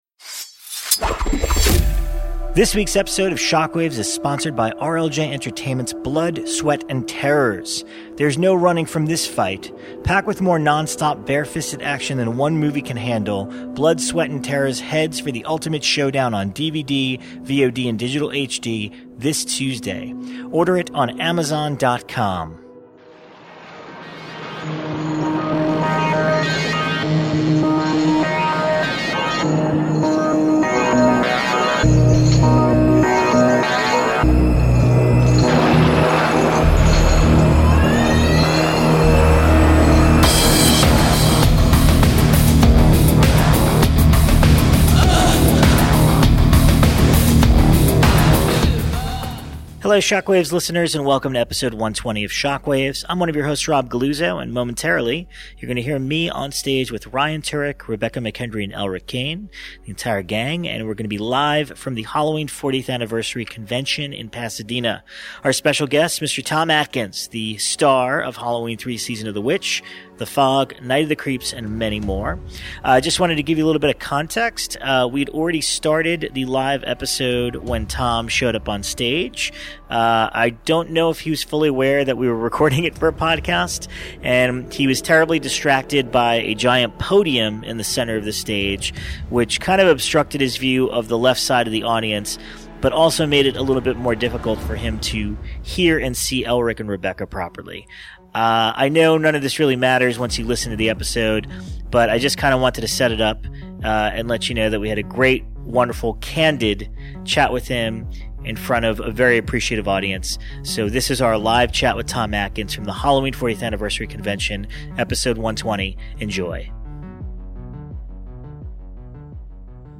Episode 120: Live from the Halloween 40th Convention with Tom Atkins!